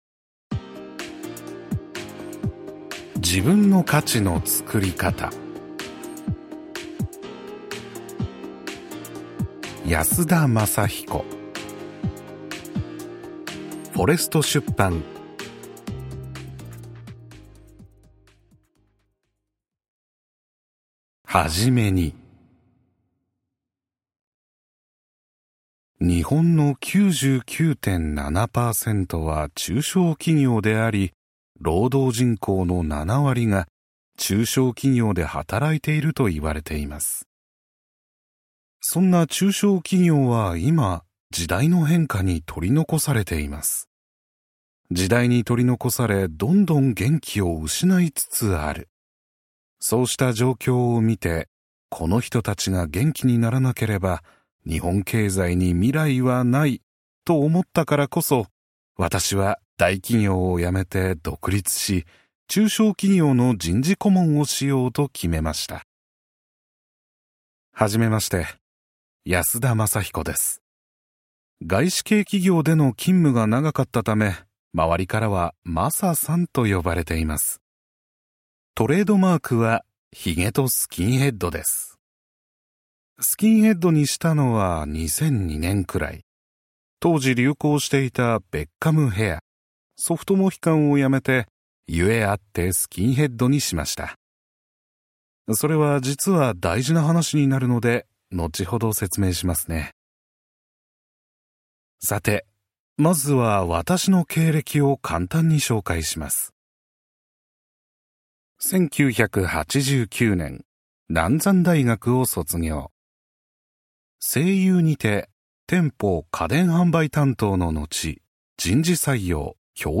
[オーディオブック] 自分の価値のつくりかた